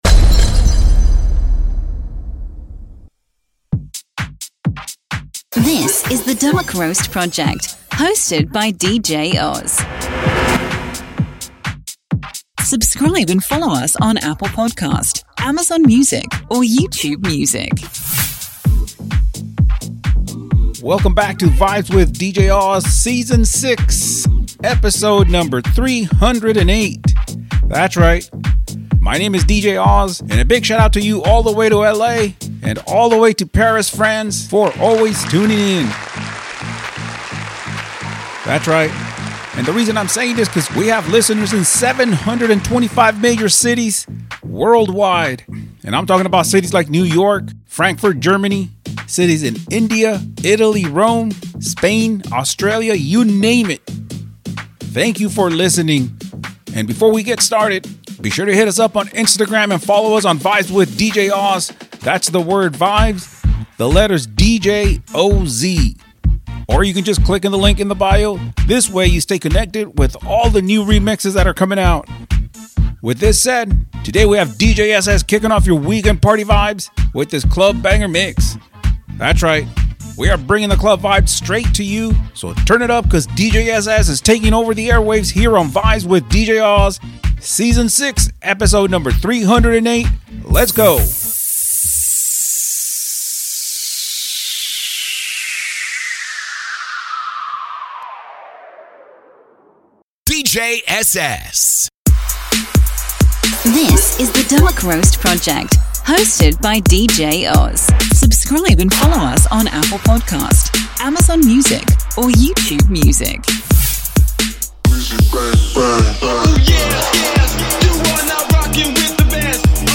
THAT’S RIGHT WE ARE BRINGING THE CLUB VIBES STRAIGHT TO YOU…